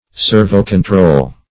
Meaning of servocontrol. servocontrol synonyms, pronunciation, spelling and more from Free Dictionary.
Search Result for " servocontrol" : The Collaborative International Dictionary of English v.0.48: Servocontrol \Ser`vo*con*trol"\, n. 1. control by a servomechanism .